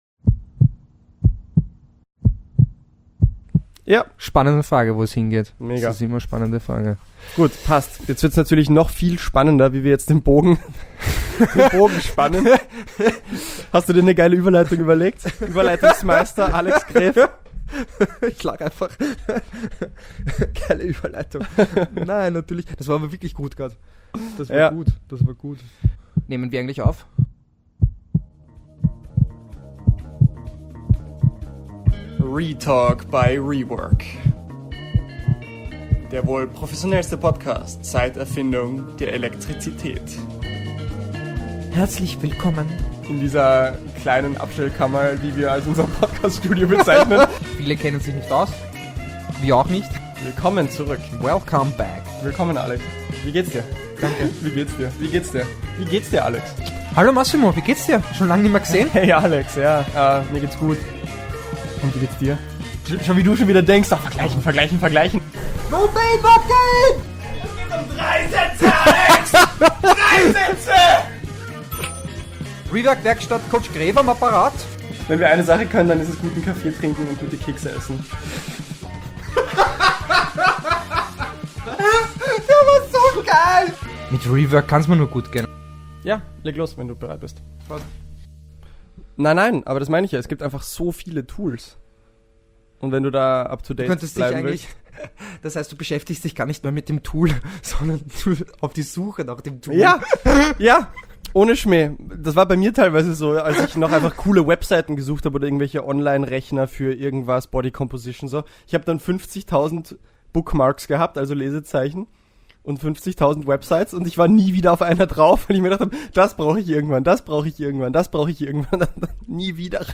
ReInterview